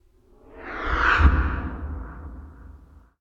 01526 swoosh 2